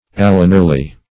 Search Result for " allenarly" : The Collaborative International Dictionary of English v.0.48: Allenarly \Al*len"ar*ly\, adv.
allenarly.mp3